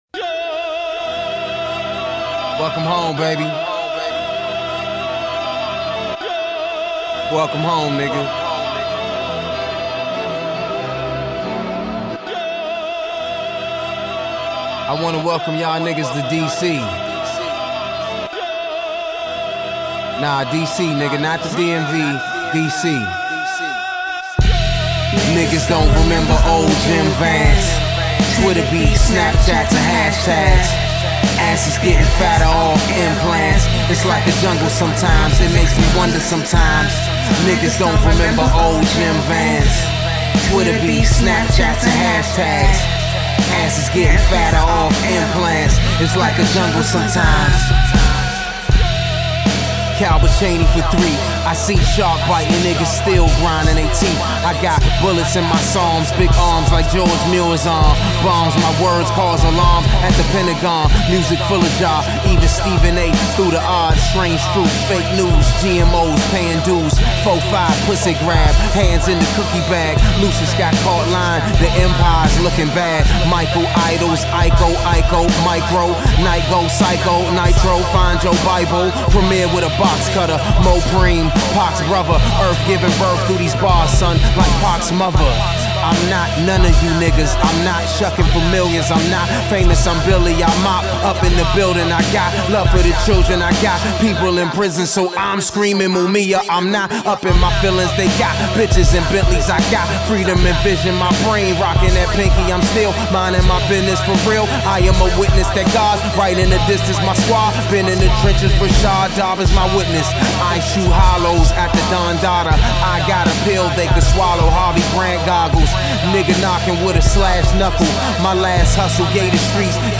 It’s an introduction to my solo voice.